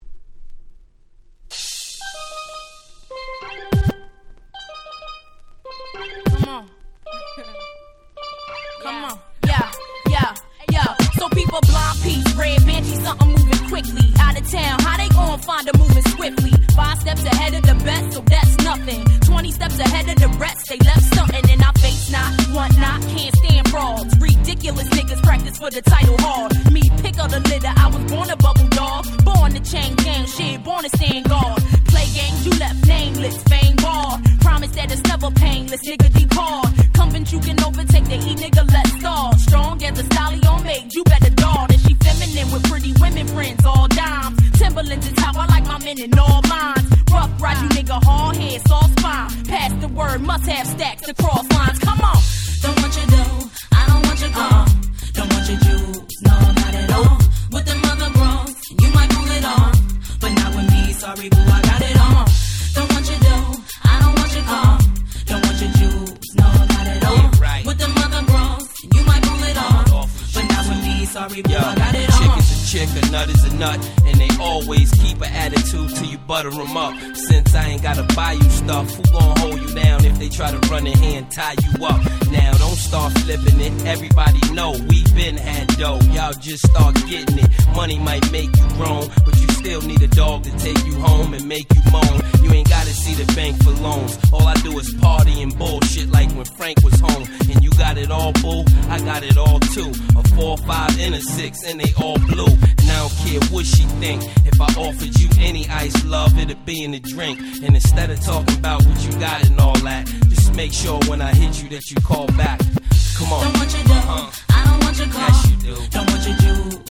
00' Smash Hit Hip Hop !!